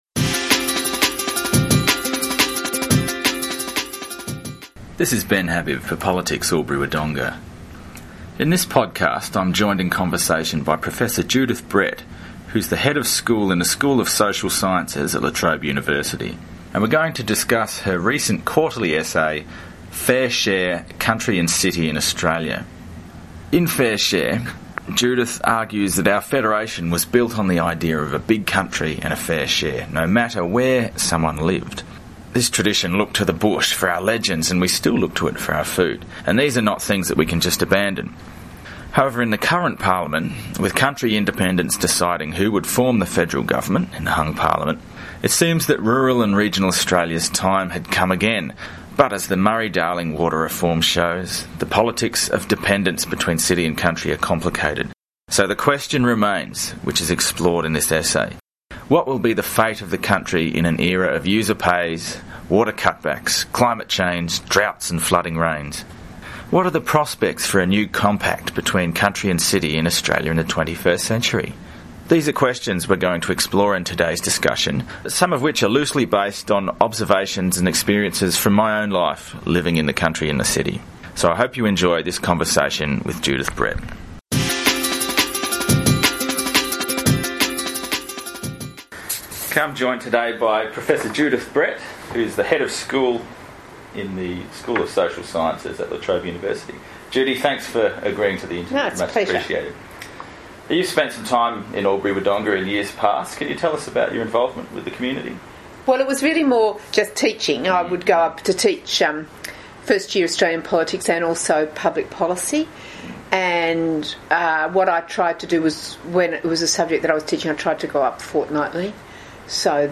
In this podcast I am joined in conversation with Professor Judith Brett, Head of School in the School of Social Sciences at La Trobe University, to discuss her Quarterly Essay entitled Fair Share: Country and City in Australia.
DOWNLOAD: Interview with Prof Judith Brett.mp3